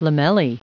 Prononciation du mot lamellae en anglais (fichier audio)
Prononciation du mot : lamellae